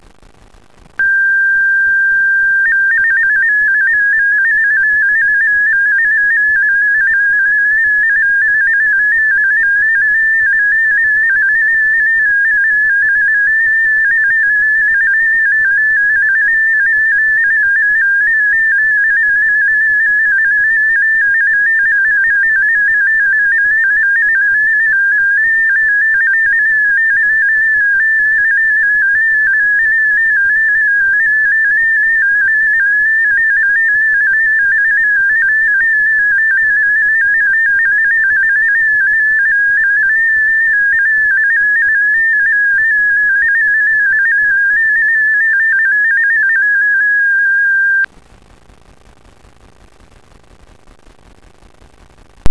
Ecco di seguito come suonano i vari modi:
mfsk16
mfsk16A.wav